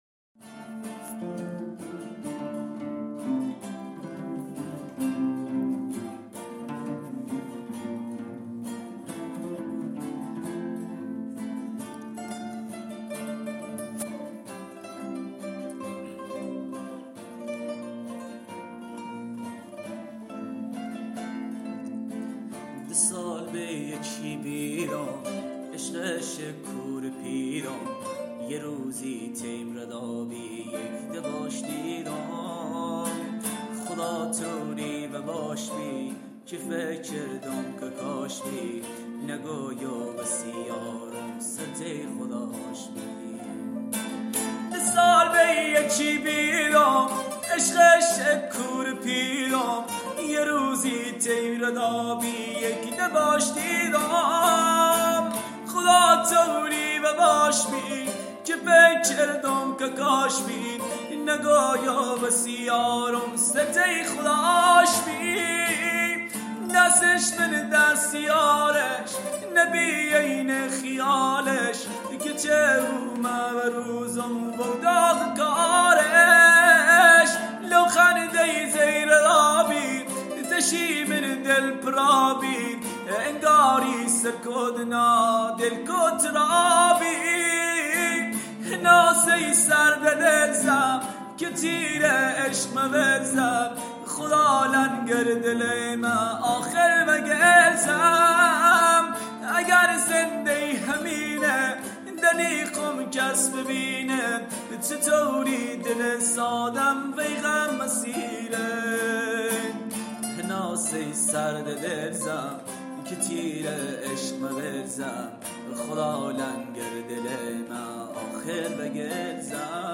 دانلود اهنگ عاشقانه و غمگین لری بویر احمدی